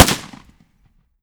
fps_project_1/30-30 Lever Action Rifle - Gunshot B 004.wav at c79e1fdfe51139bc16eb15aa8b42e55375a869a8 - fps_project_1 - Gitea: Git with a cup of tea